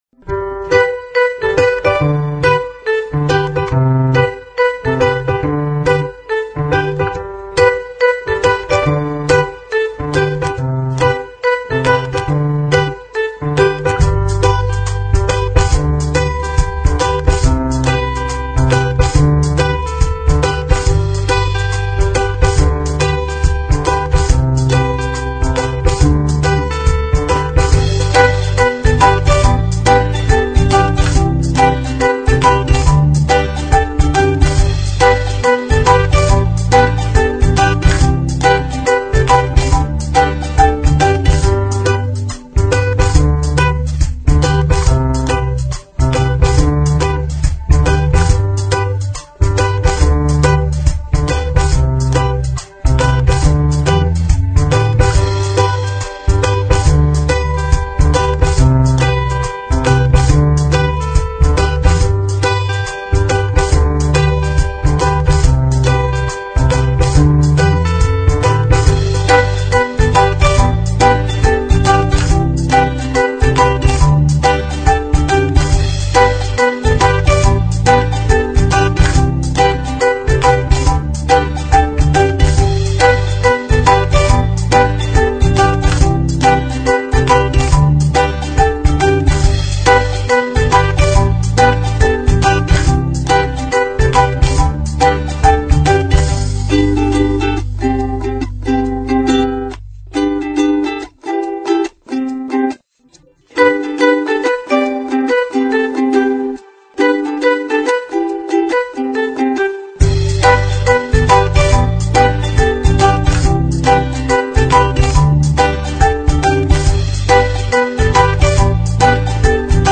Sample Rate 采样率16-Bit Stereo 16位立体声, 44.1 kHz